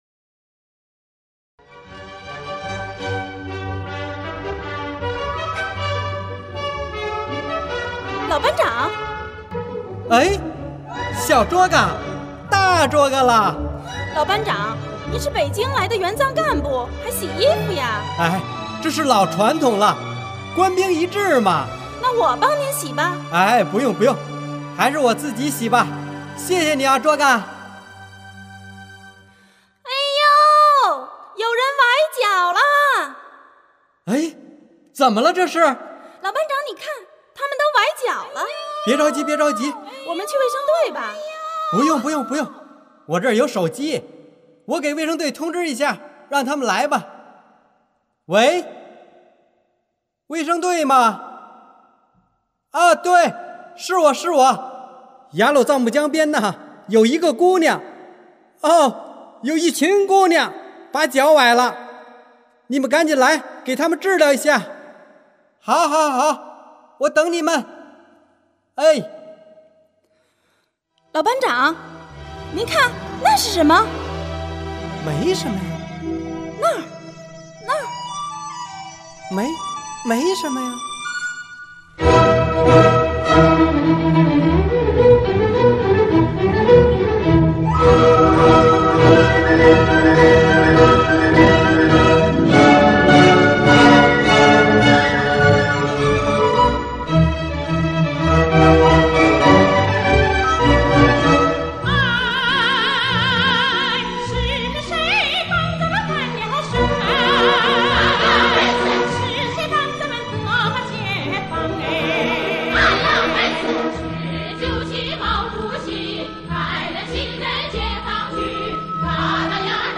Sound demo
E216 Opera
E216_Opera.mp3